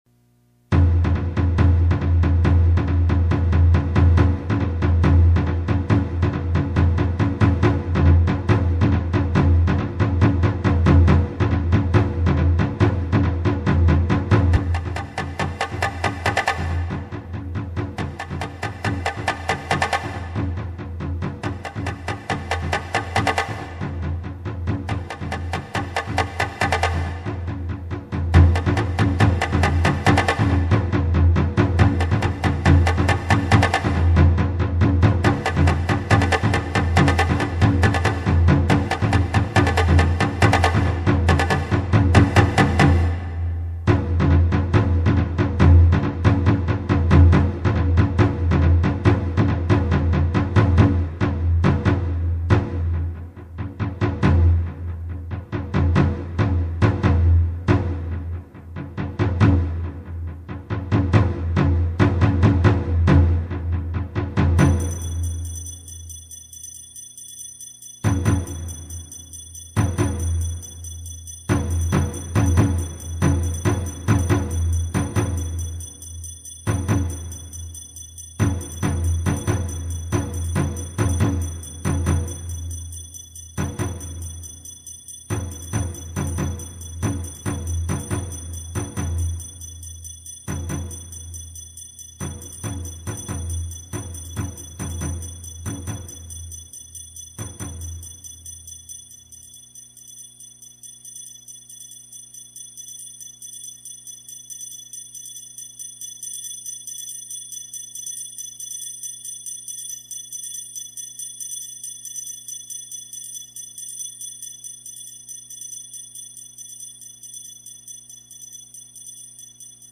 使用楽器　　大太鼓・中太鼓・締太鼓・当たり鉦・チャッパ・しの笛